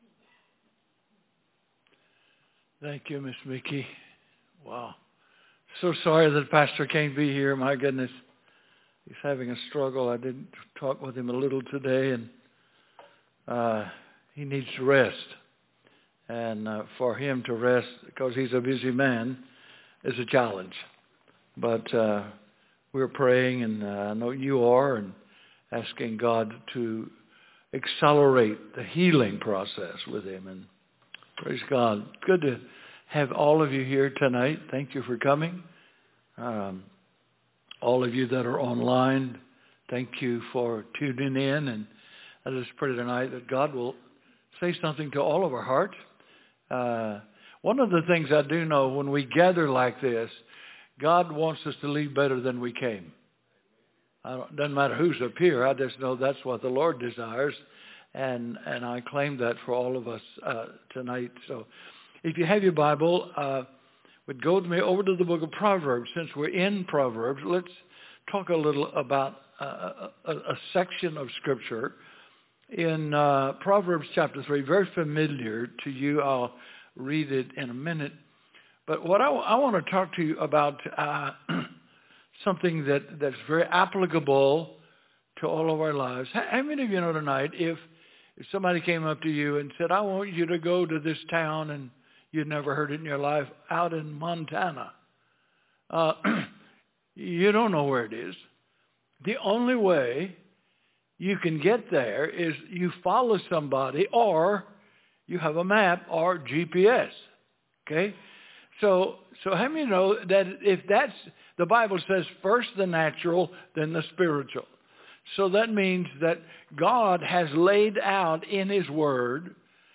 Sermons | Grace House